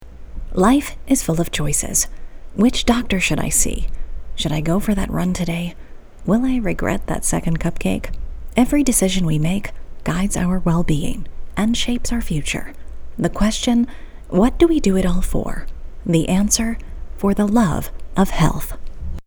Healthcare US